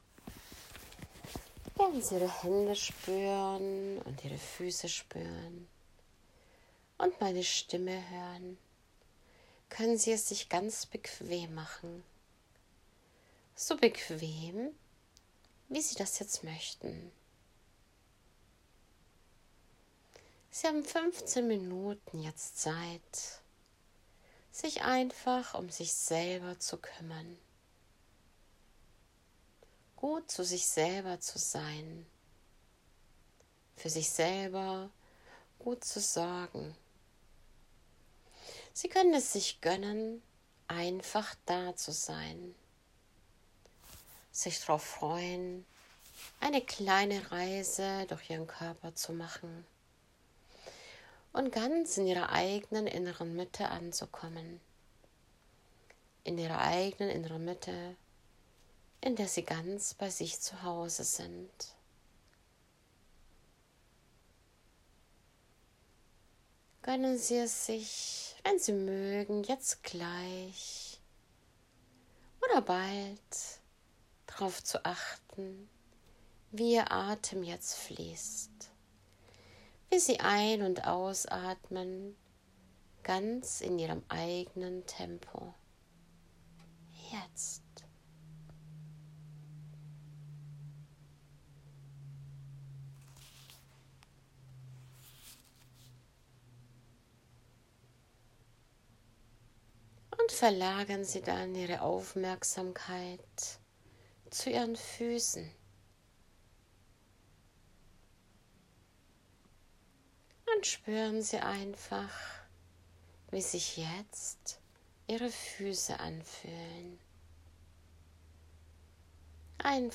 Entspannungsreise: Reise durch den eigenen Körper und zur eigenen inneren Mitte